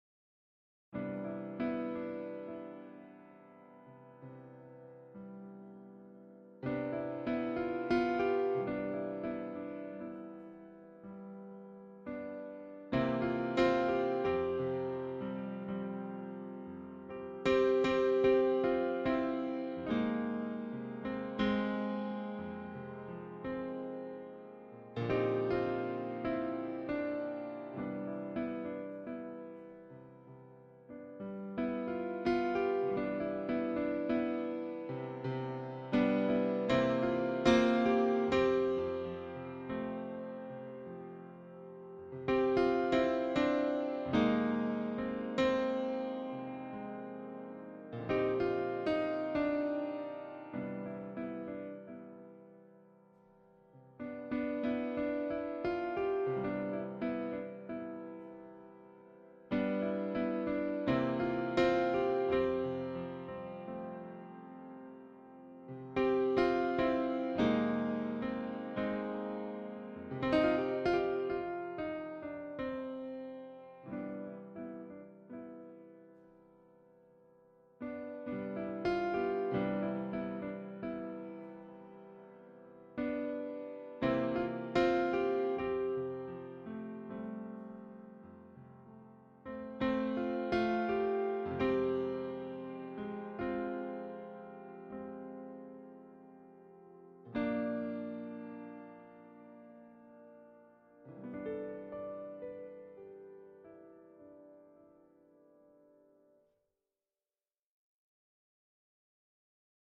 3 chord jazz impro
Just trying out a few things with some jazzy chords.